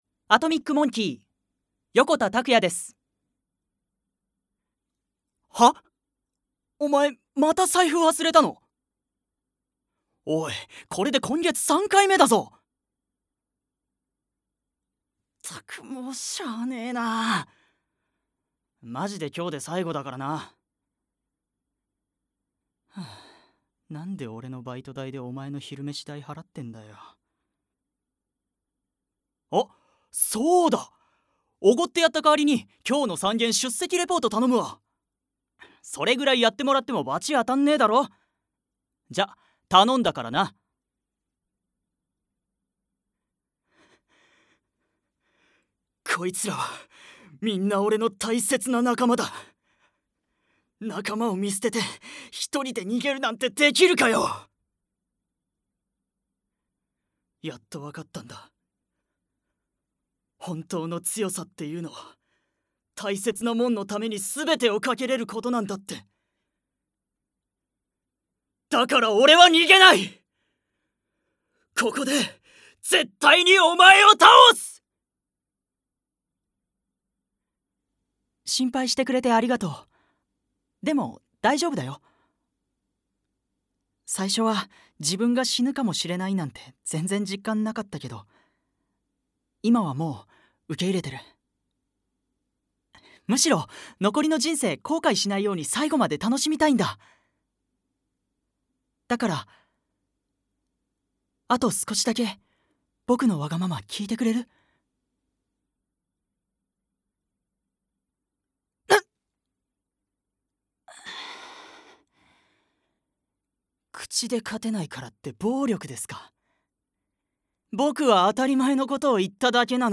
方言 関西弁（明石）